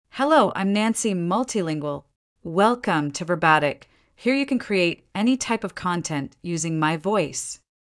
FemaleEnglish (United States)
Nancy MultilingualFemale English AI voice
Nancy Multilingual is a female AI voice for English (United States).
Voice sample
Listen to Nancy Multilingual's female English voice.
Nancy Multilingual delivers clear pronunciation with authentic United States English intonation, making your content sound professionally produced.